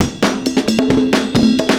Index of /90_sSampleCDs/USB Soundscan vol.46 - 70_s Breakbeats [AKAI] 1CD/Partition A/27-133PERCS9